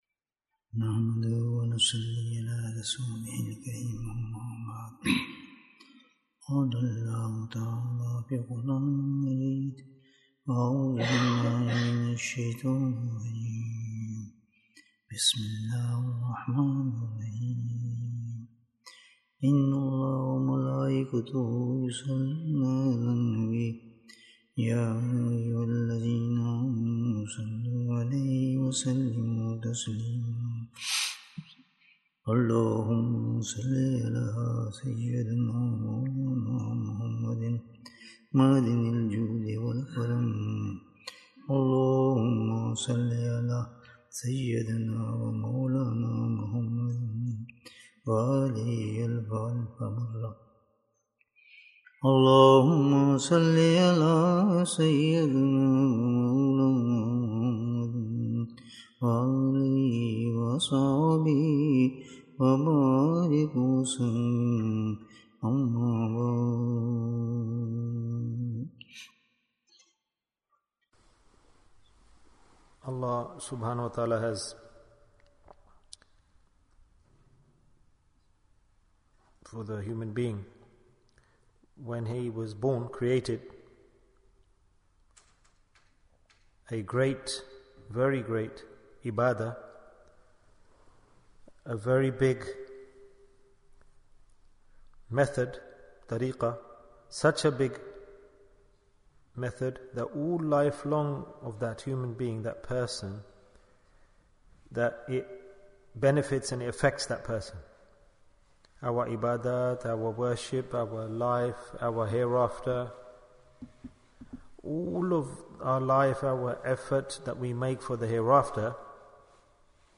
A Gift on Your Birth From Allah Bayan, 63 minutes23rd February, 2023